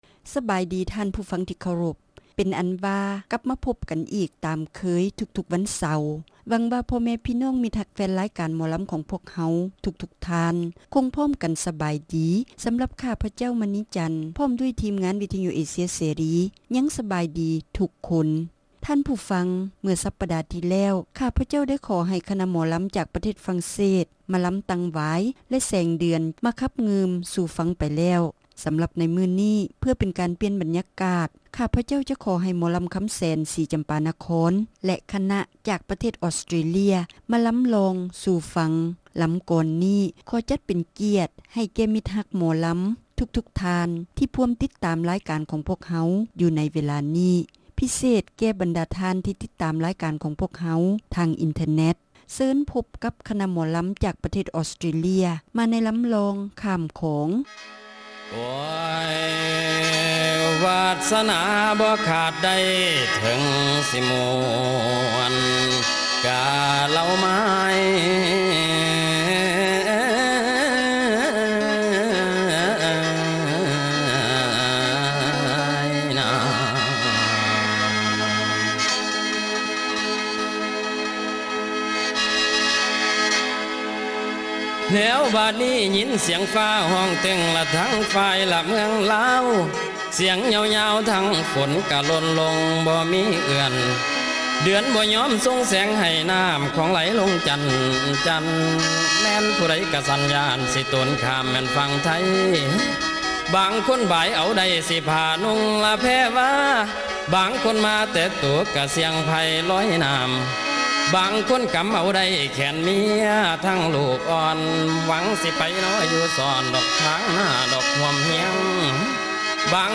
ຣາຍການໜໍລຳ ປະຈຳສັປະດາ ວັນທີ 28 ເດືອນ ກັນຍາ ປີ 2007